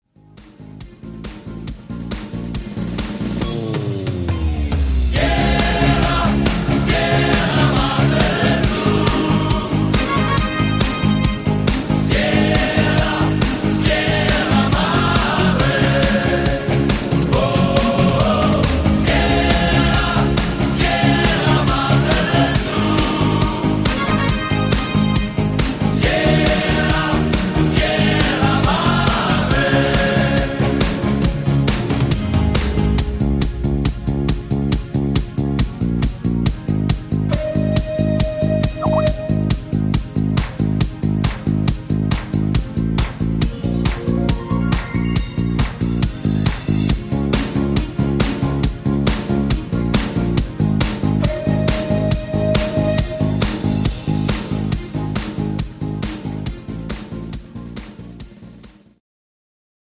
Disco-Party-Version